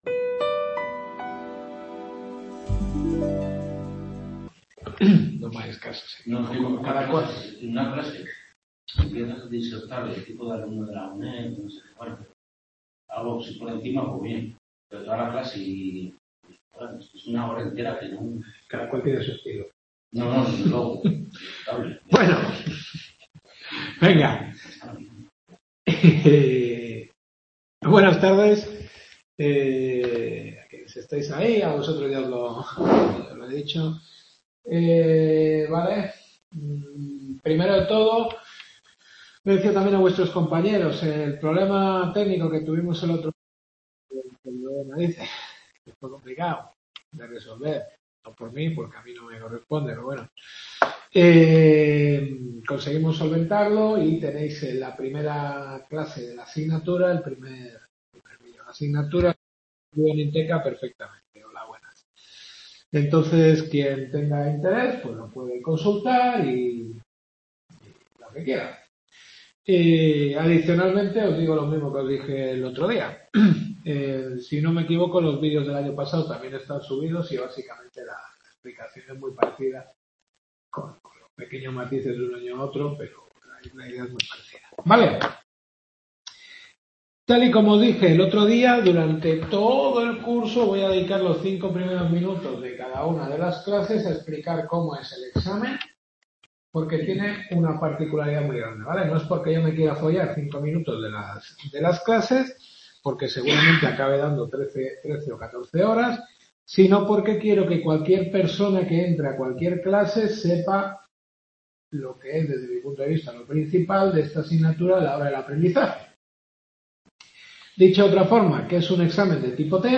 Derecho Administrativo Europeo. Segunda Clase.